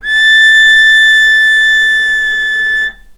vc-A6-mf.AIF